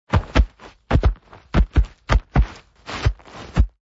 Звуки гориллы